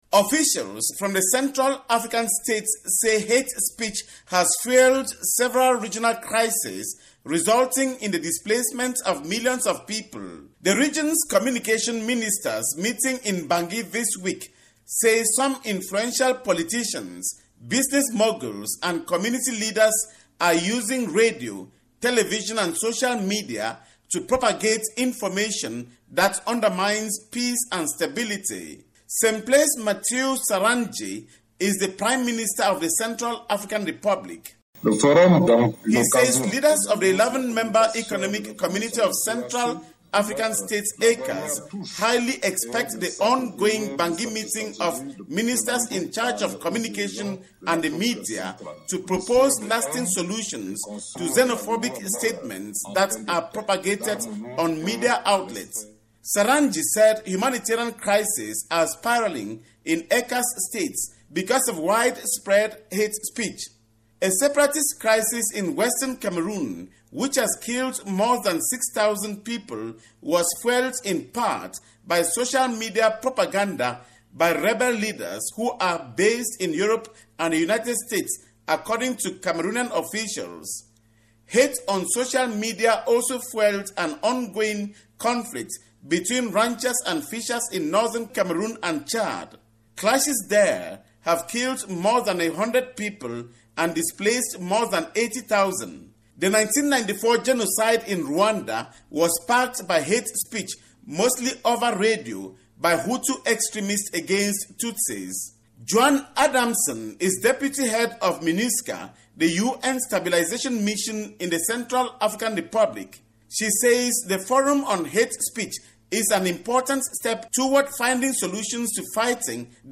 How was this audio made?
reports from neighboring Cameroon